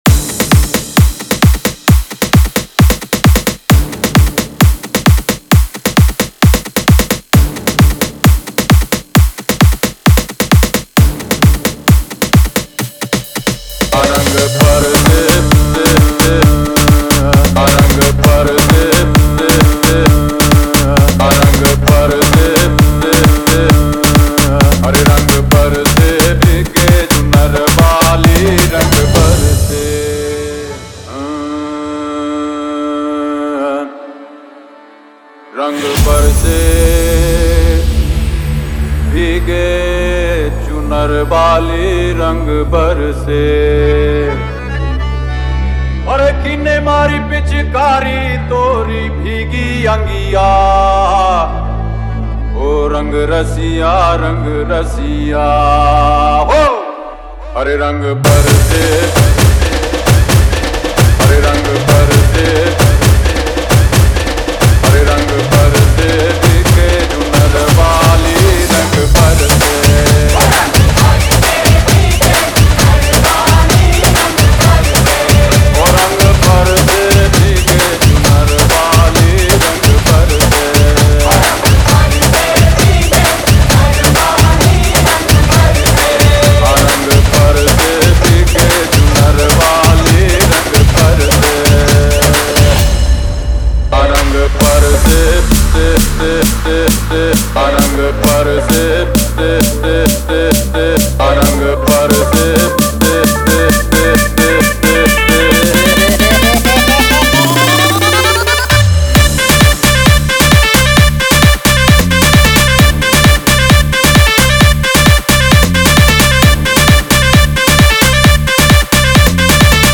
HOLI SPECIAL DJ SONG Songs Download